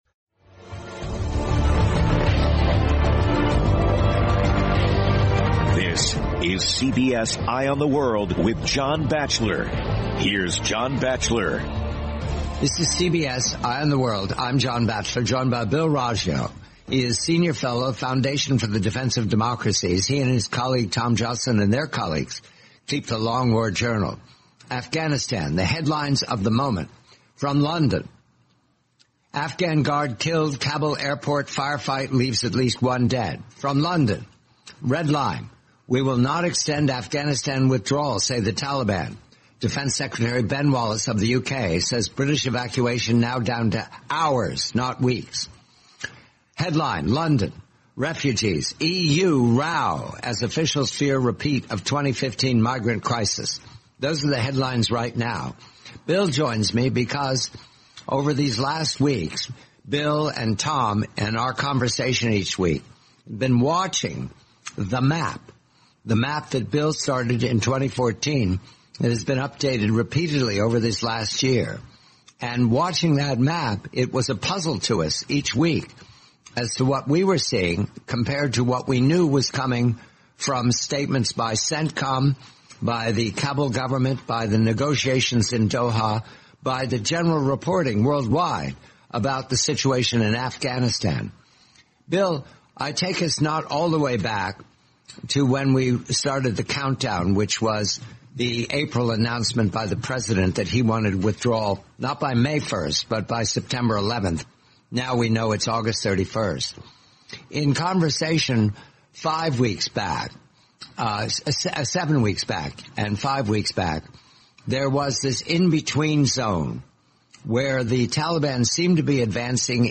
forty-minute interview